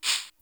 JC_SHKR1.WAV